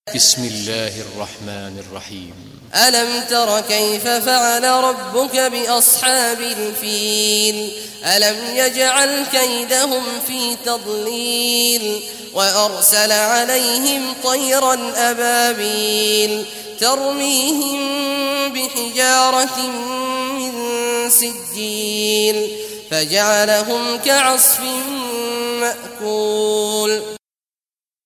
Surah Al-Fil Recitation by Sheikh Awad al Juhany
Surah Al-Fil, listen or play online mp3 tilawat / recitation in Arabic in the beautiful voice of Imam Sheikh Abdullah Awad al Juhany.